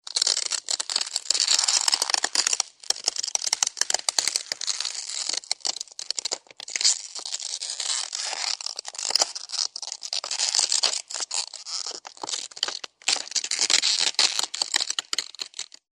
Звуки треска льда
Треск льда под ногами